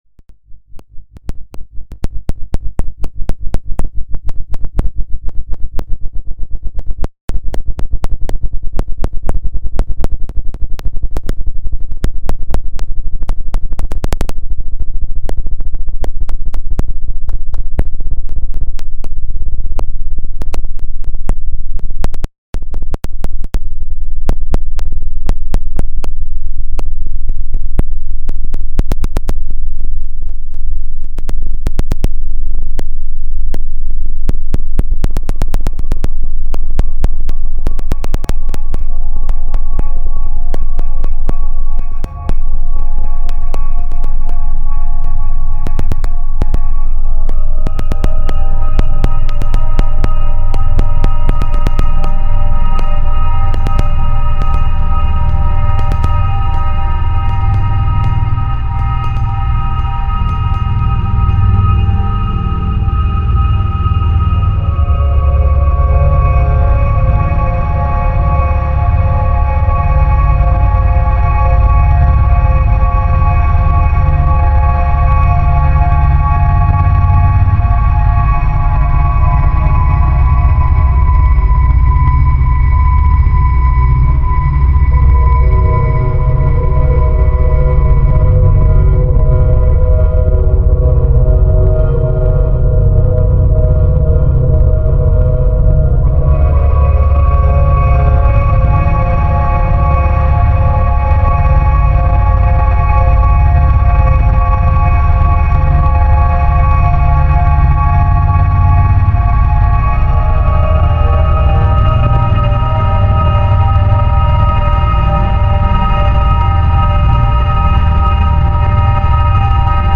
These are some of my old beats.